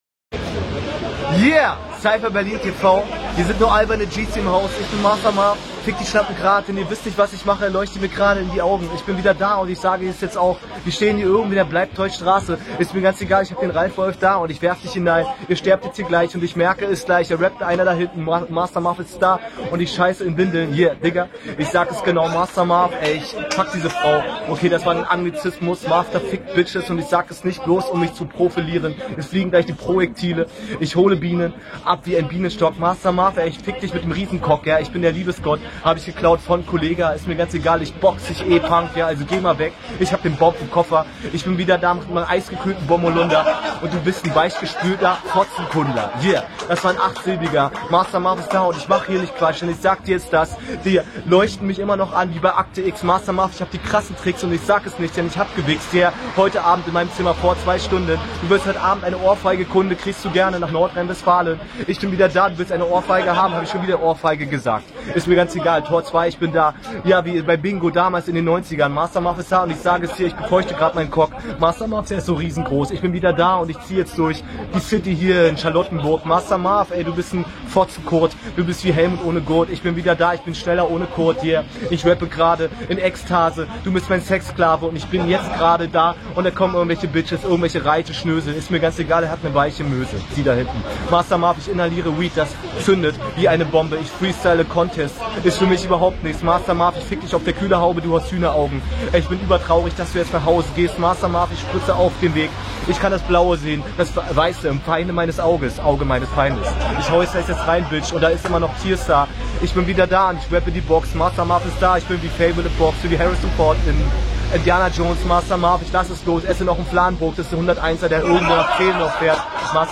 Schnelles Battle Format
Driveby Runde